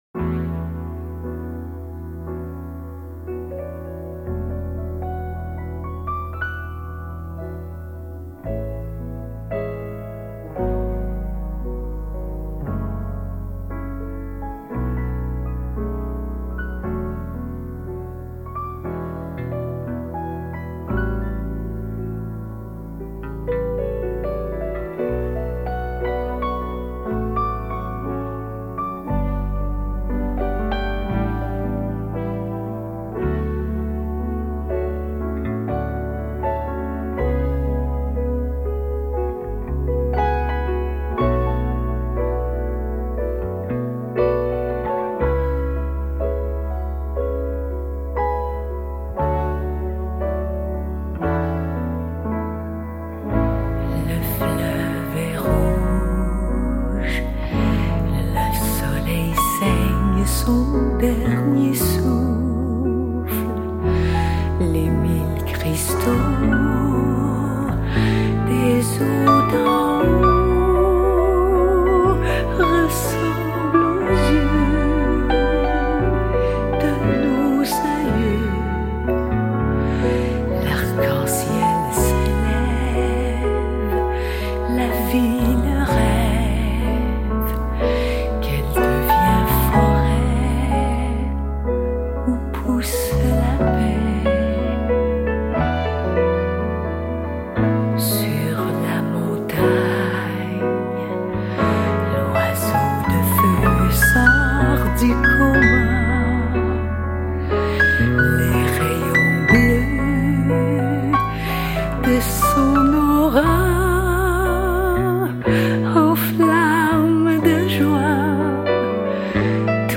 a peaceful and jazzy album